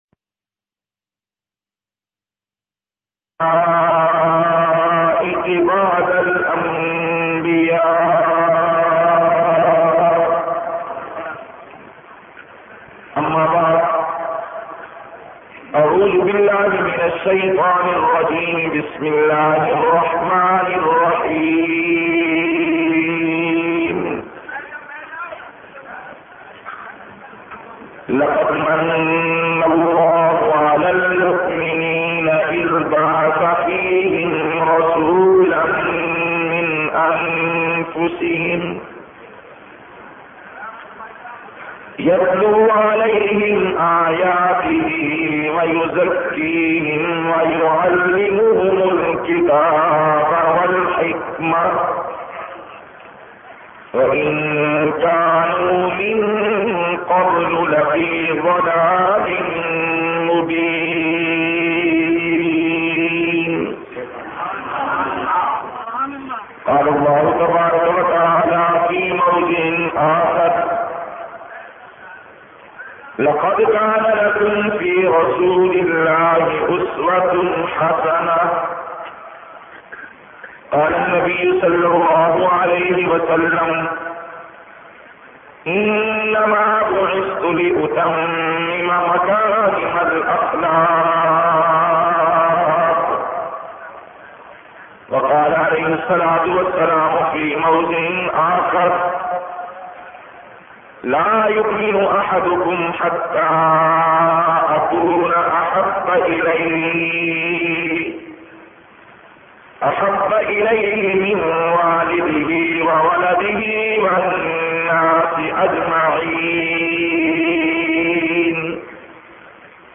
86- Paighambar e Inqalab Conference Karachi company Islamabad.mp3